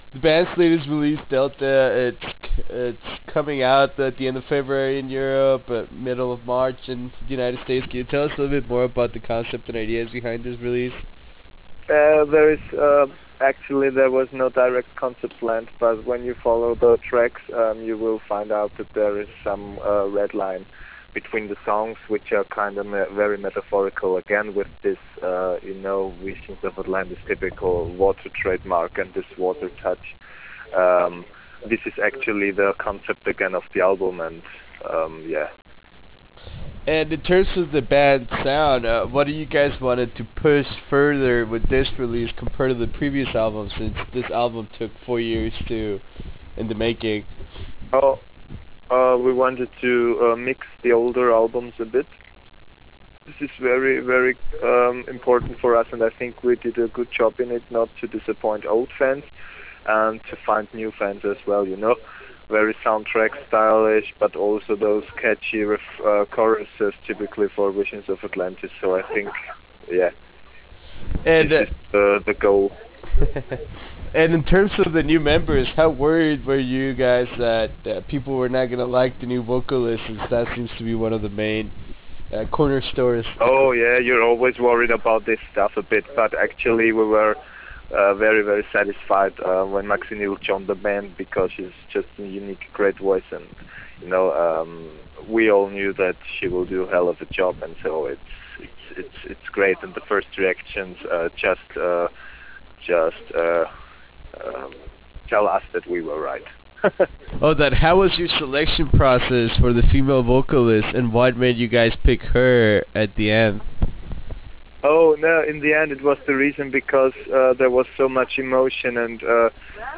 In this very short interview (5+ minutes!) we managed to discus the concept and ideas behind the band’s latest release “Delta”. We also talked about the new vocalist selection process and what’s in store for the band in the future in terms of touring North America.